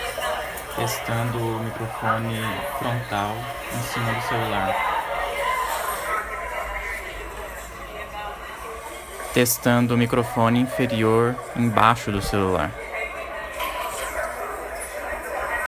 Em seguida, coloquei um barulho de cafeteria ao fundo e falei ao celular, primeiro com ele posicionado como os da imagem lá de cima, ou seja, com a parte de cima apontada para a minha boca, e depois ao contrário, como é o indicado.
Repare que, além da minha voz ficar mais alta e definida no segundo teste, segurado do modo certo o iPhone ainda dá uma amenizada bem perceptível no ruído de fundo.
Teste-Microfones.m4a